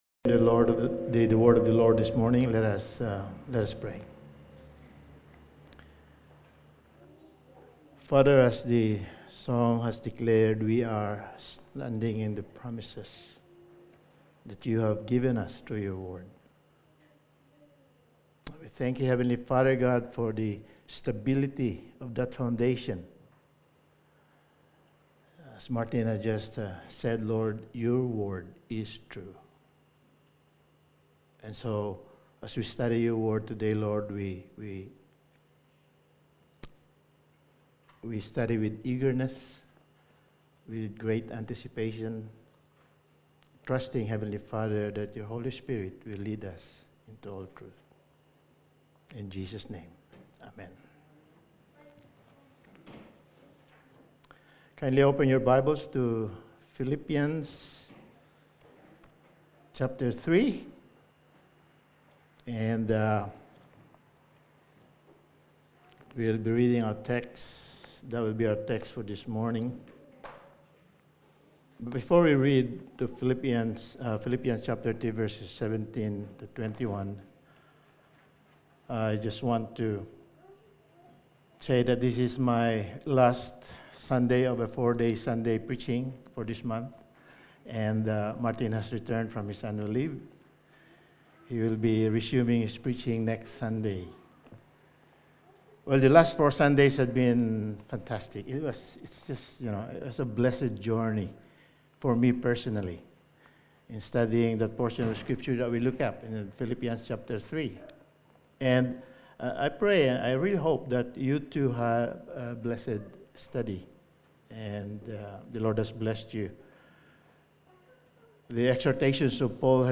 Passage: Philippians 3:17-21 Service Type: Sunday Morning